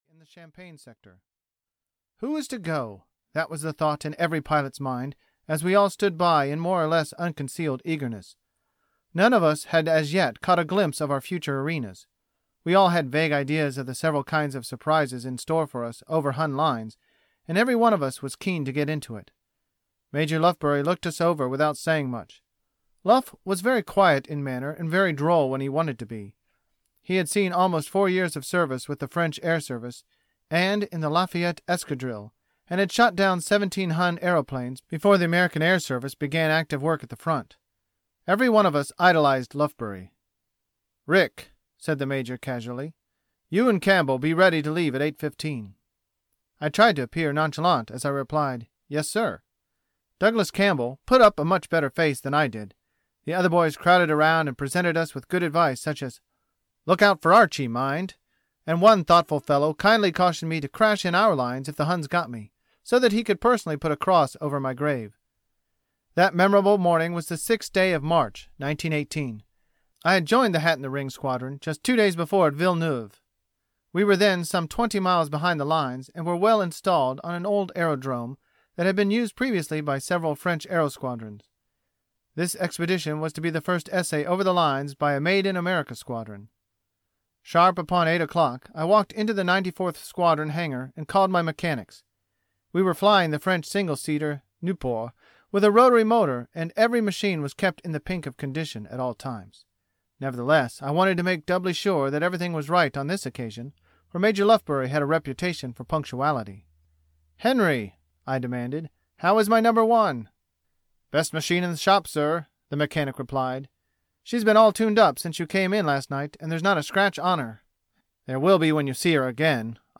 Fighting the Flying Circus (EN) audiokniha
Ukázka z knihy